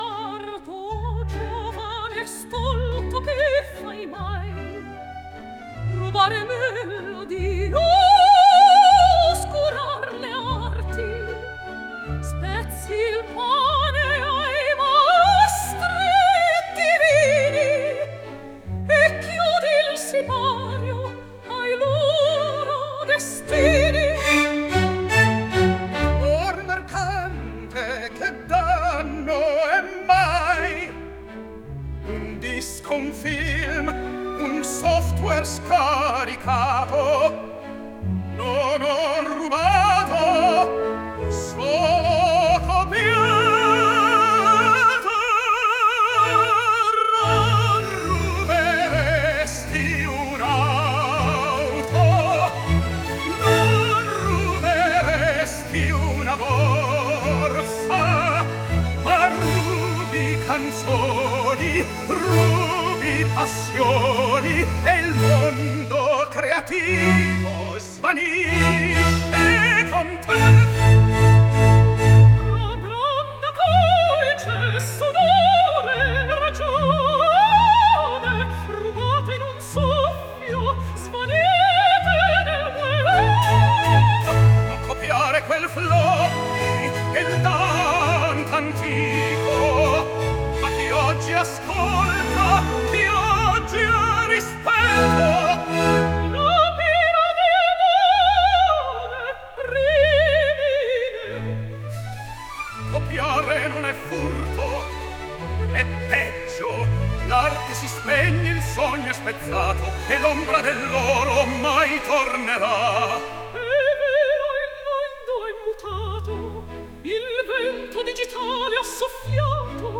in stile operistico